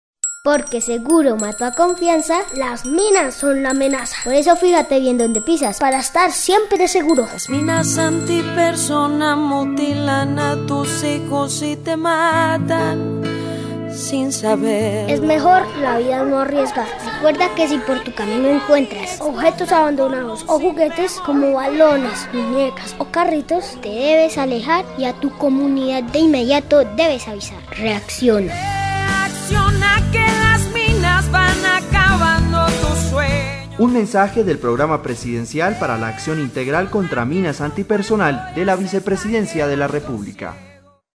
La campaña incluye siete comerciales de televisión sobre los riesgos y las orientaciones para asumir comportamientos seguros; cinco cuñas radiales con los mensajes básicos de prevención y las voces de importantes artistas como Maia y el grupo San Alejo, entre otros.
Cuñas radiales